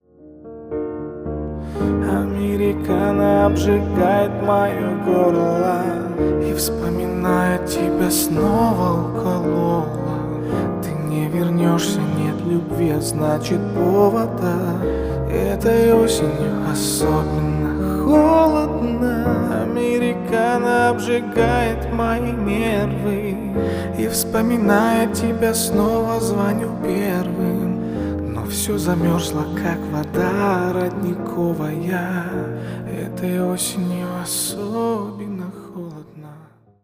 Поп Музыка # спокойные # грустные